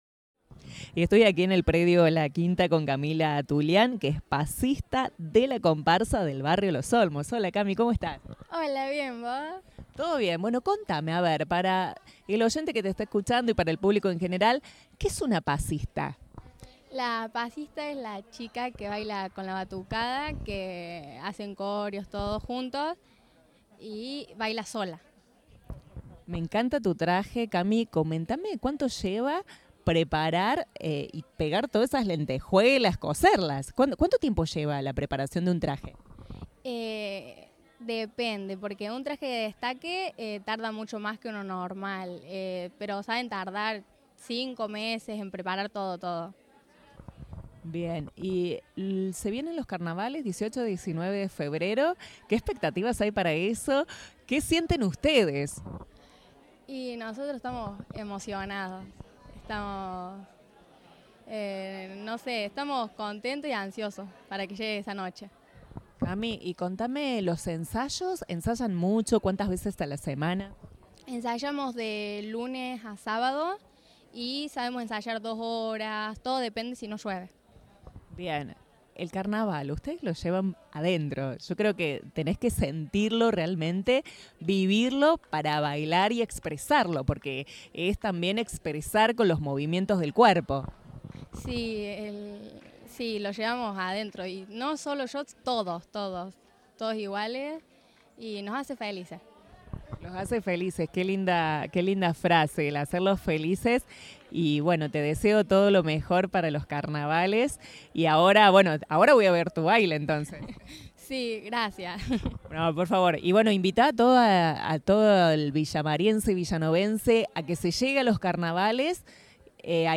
En la presentación, Radio Centro logró la palabra de una de las pasistas que dirán presente en los Carnavales, representando al Barrio Los Olmos.